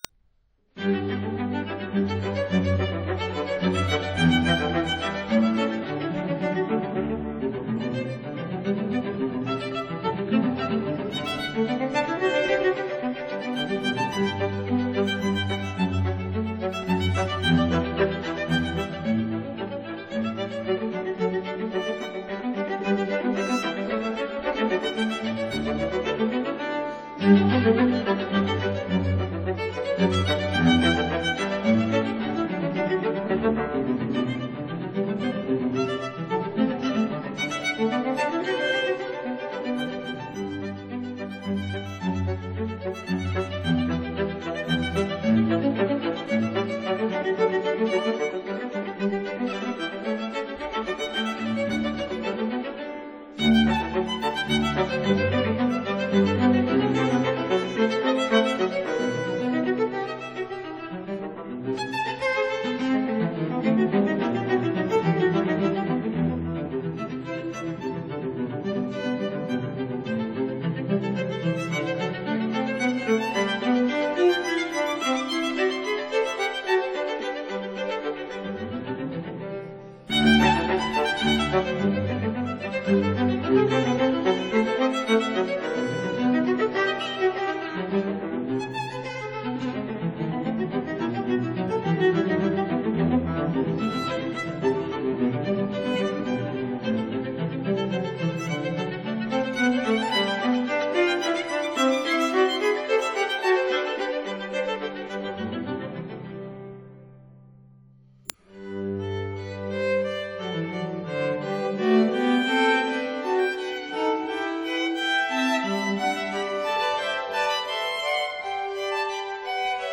violin
viola
cello